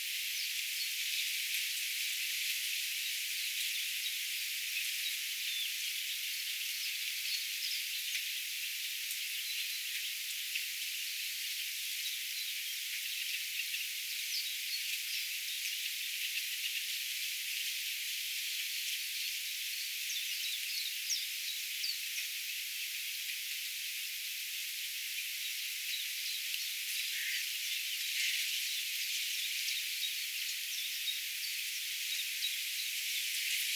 hiljaisesti kuuluu hieman rytikerttusen laulua
hiljaisesti_kuuluu_rytikerttusen_laulua_ehka_pari_sataa_metria_oikealle_haarapaaskysen_pesapaikalta.mp3